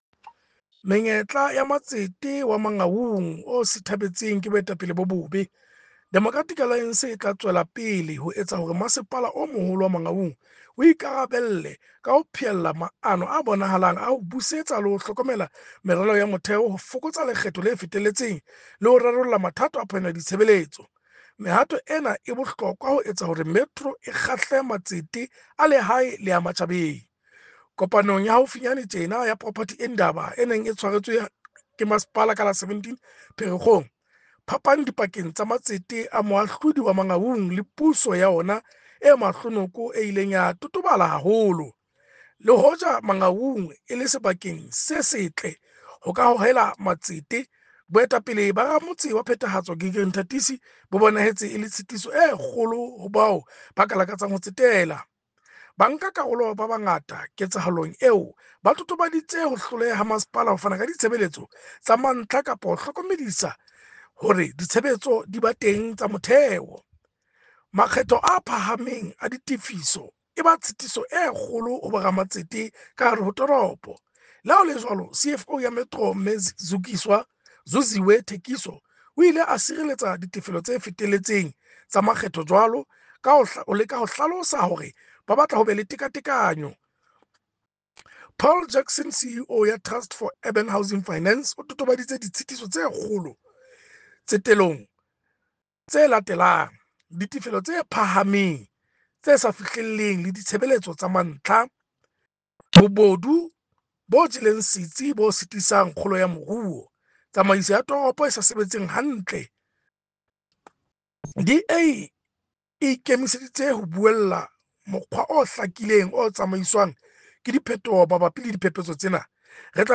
Sesotho soundbite by Cllr Kabelo Moreeng